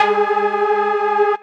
Mainlead_Melody16.ogg